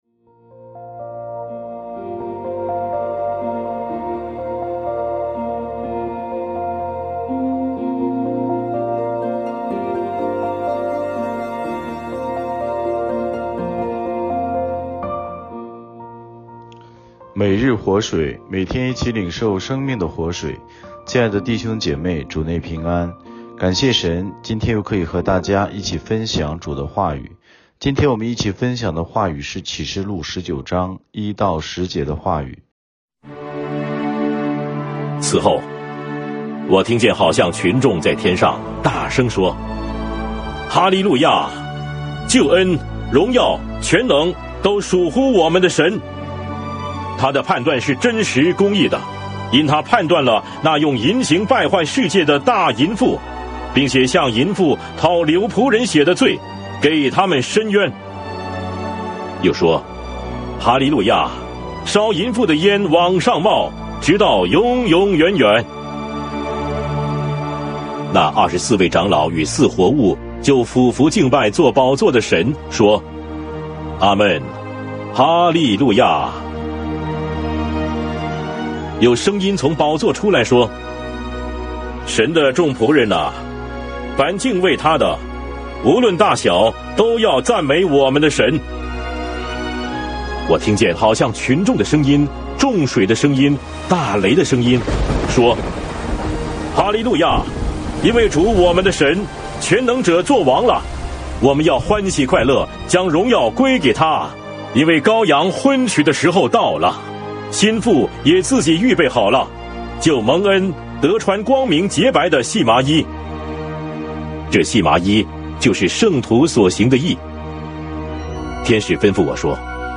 牧/者分享